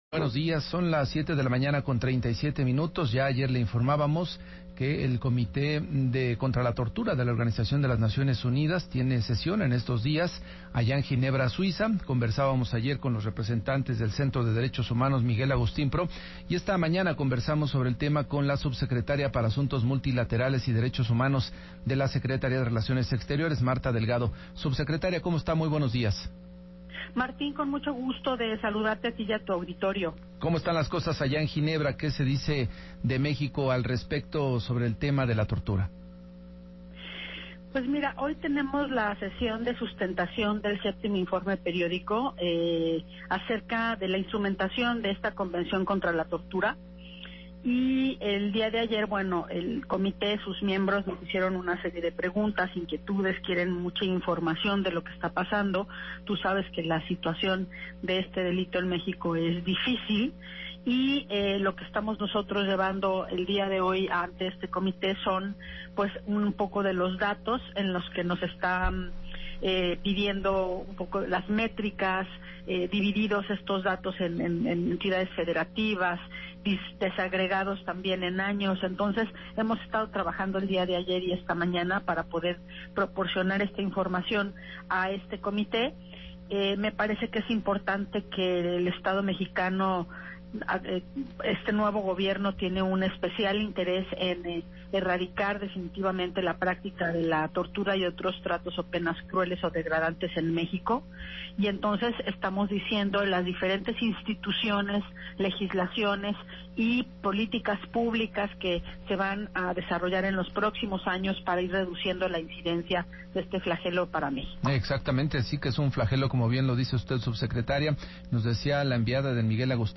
El 26 de abril de 2019, Martha Delgado, subsecretaria para Asuntos Multilaterales y Derechos Humanos de la Secretaría de Relaciones Exteriores platicó en entrevista sobre la sustentación del informe ante el Comité contra la Tortura de Naciones Unidas.
[Audio] Entrevista en Enfoque sobre sustentación de informe ante CAT